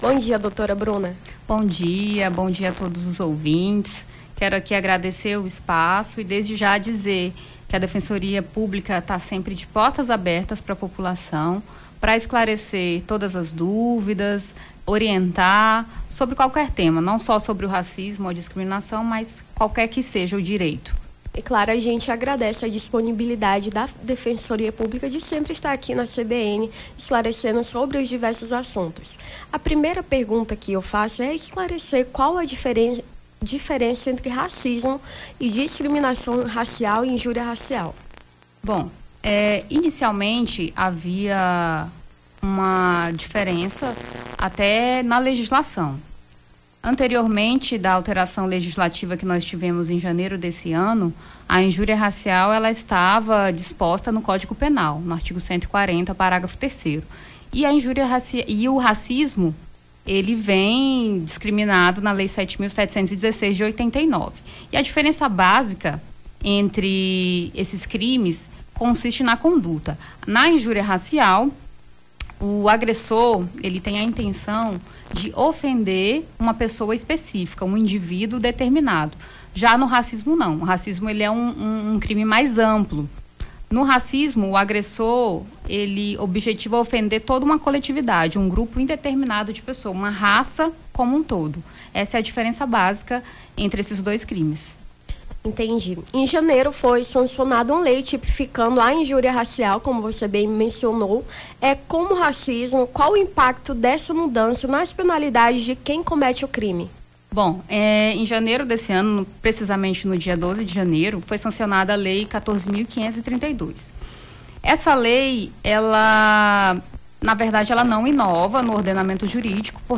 Nome do Artista - CENSURA - ENTREVISTA (SEUS DIREITOS DEFENSORIA) 10-07-23.mp3